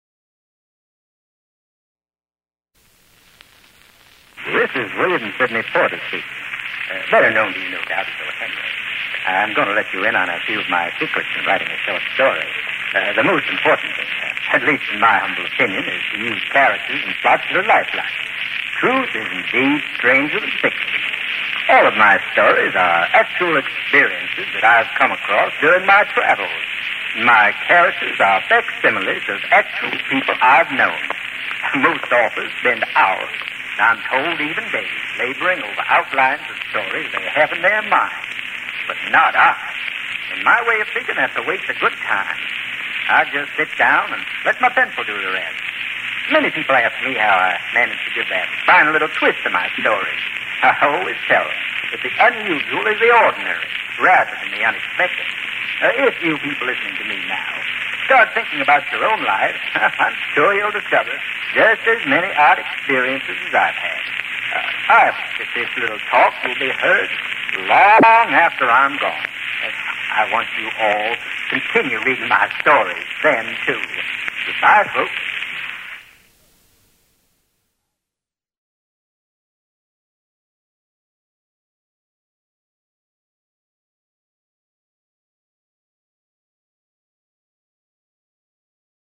Copied from the original disc owned by the Austin History Center, Austin, Texas.